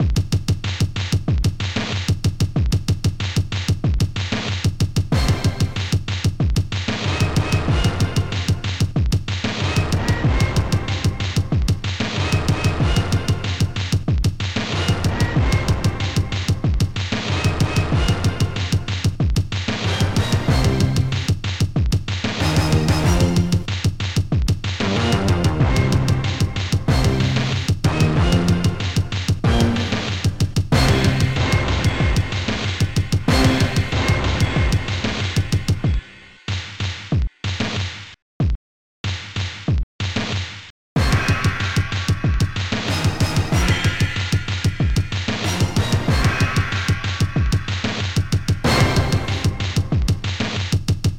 Protracker Module
bass Bass Drum snare snare bdrum orchhit CYMBAL